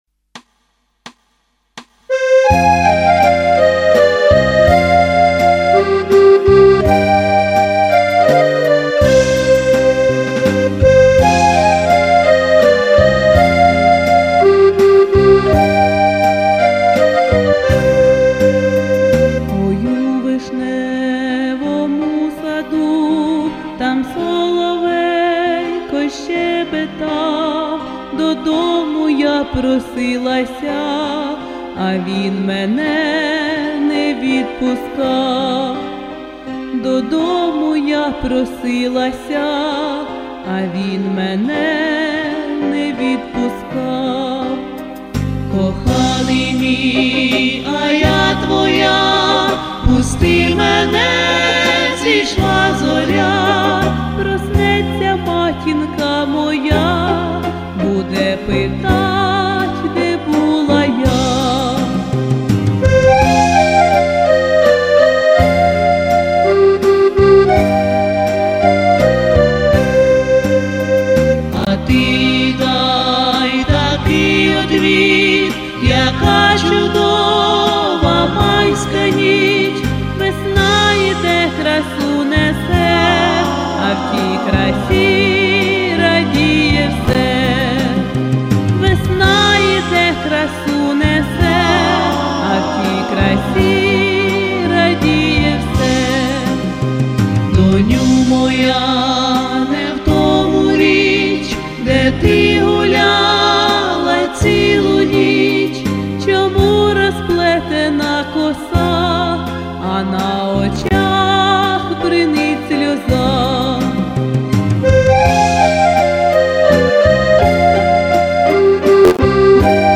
Украинские Народные песни